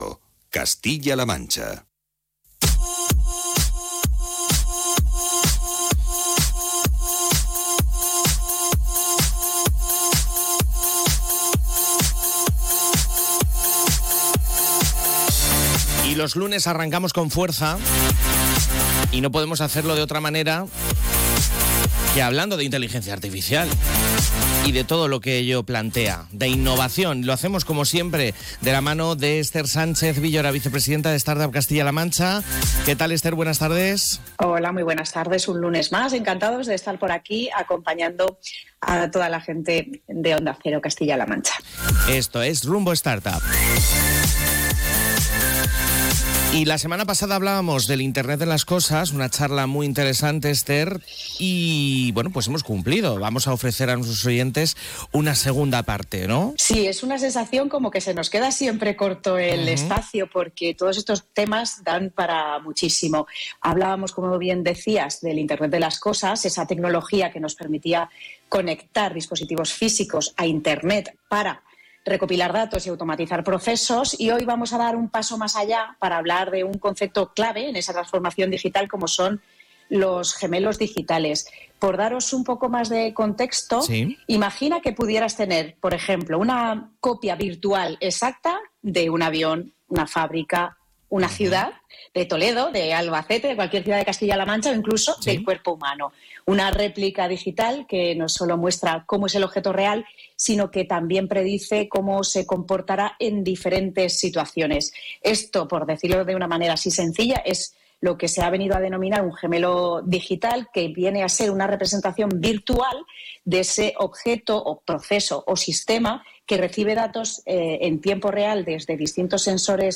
En una charla muy enriquecedora